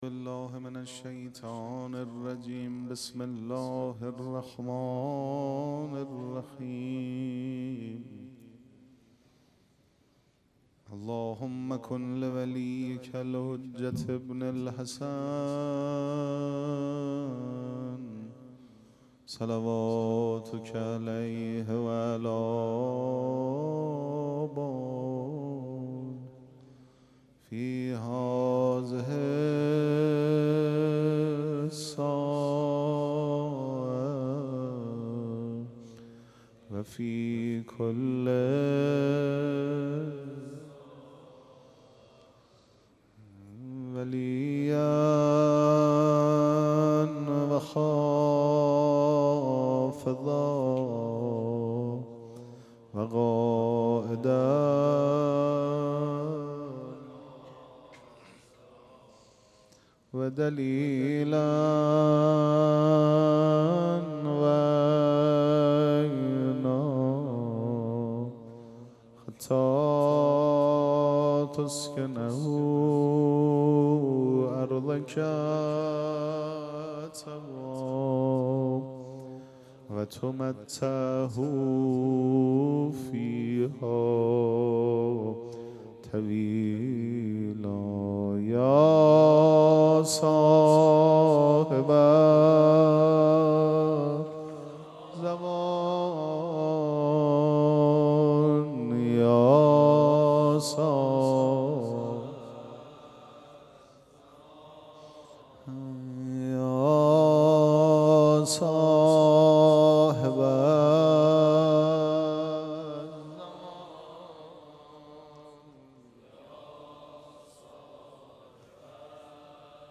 ذکر توسل
مراسم عزاداری شب چهارم محرم الحرام ۱۴۴۷
پیش منبر